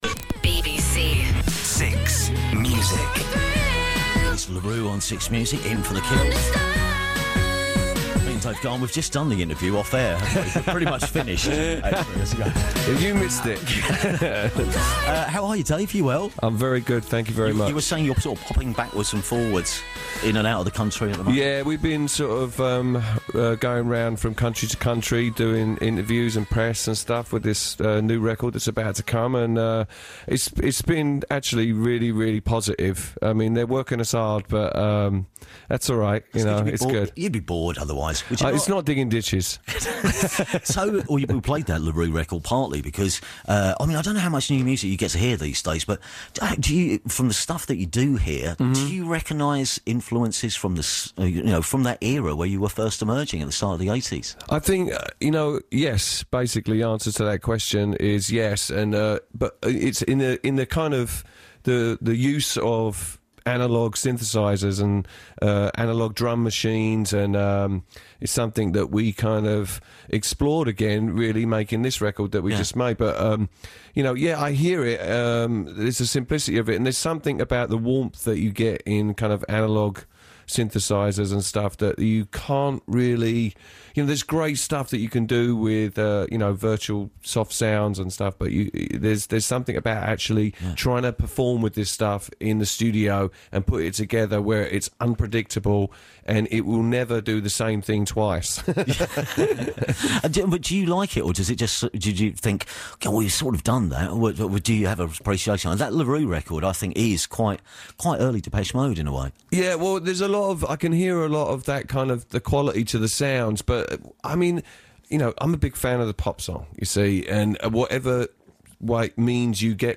Here is an extended extract from that interview where they not only talked through the themes of the record, but also touched up upon the band’s love of second hand analogue equipment and how his kids love finding old pictures of him on the internet.